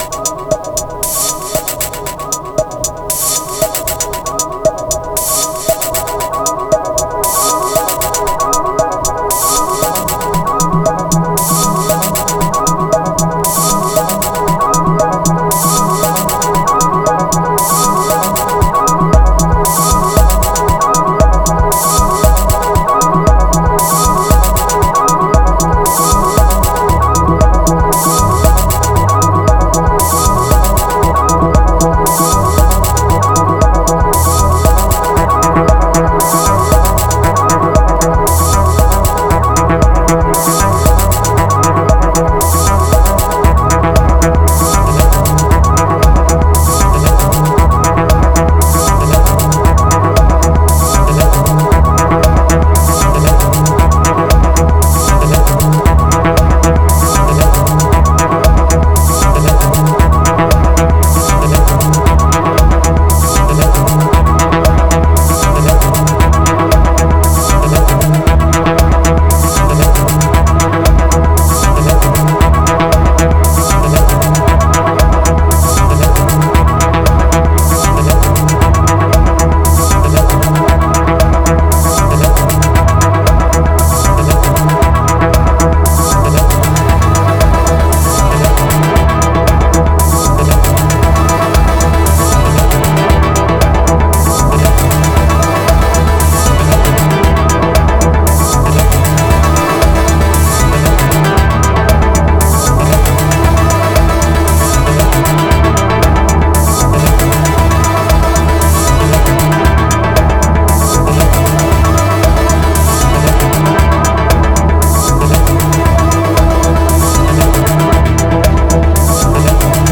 601📈 - 93%🤔 - 116BPM🔊 - 2025-08-12📅 - 624🌟